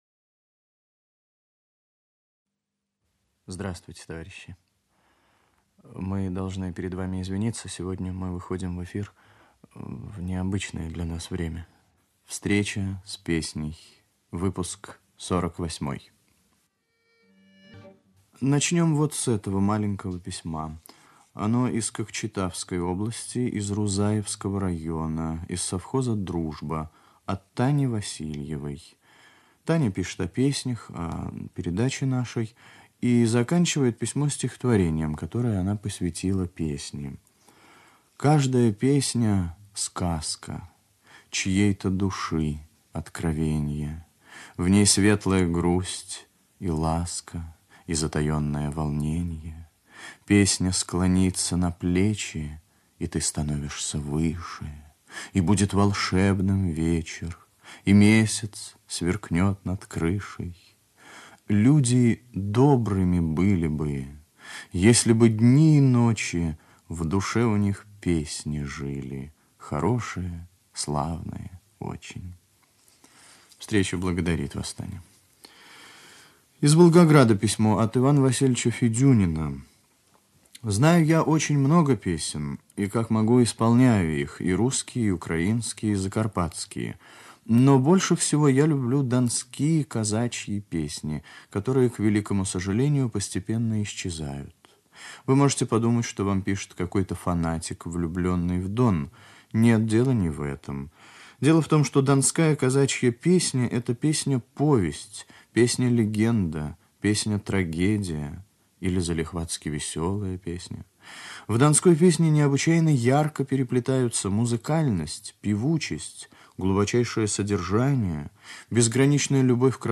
1 Русская народная песня
бас